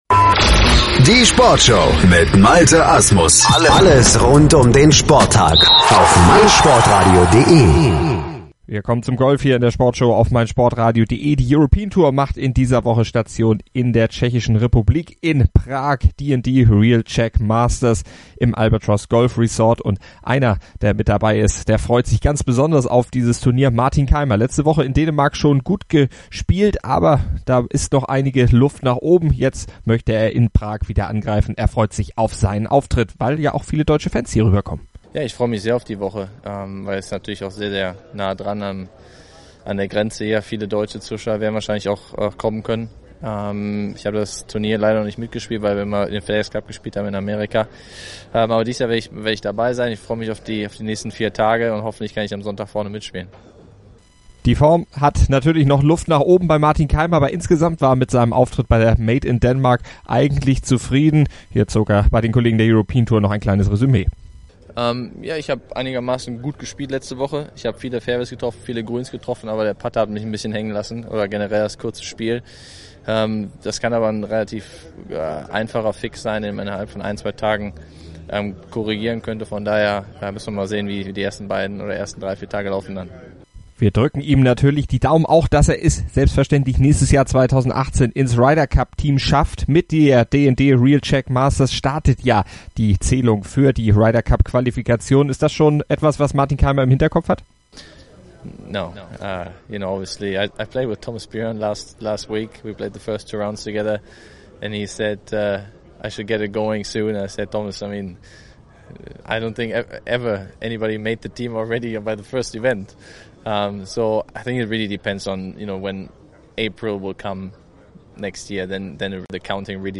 er im Interview auch, dass die heiße Phase für ihn natürlich erst